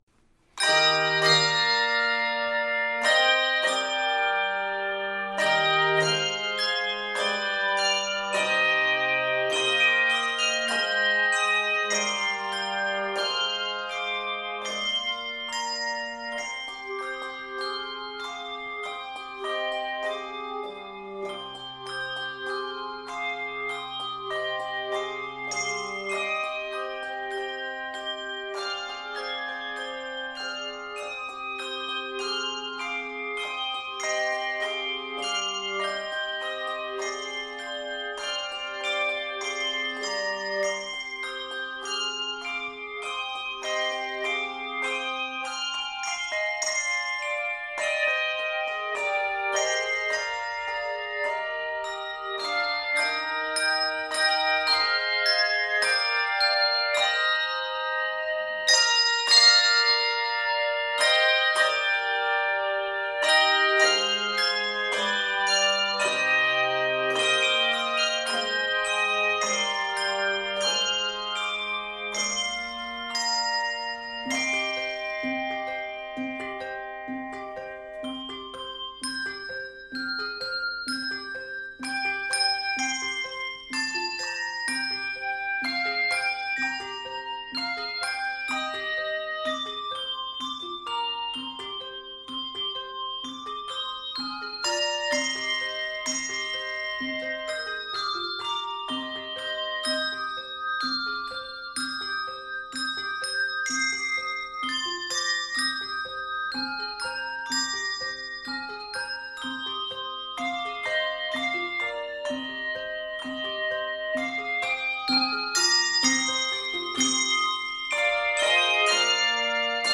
Keys of c minor and F Major.